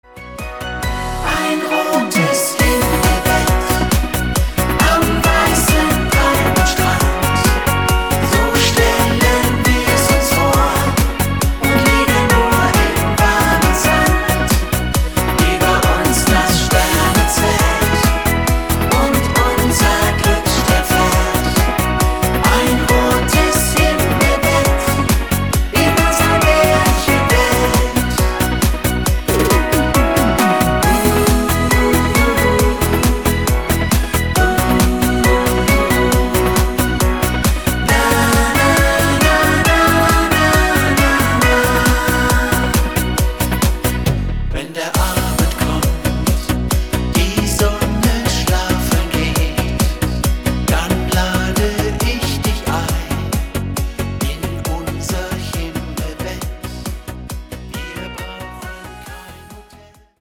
absoluter Schlager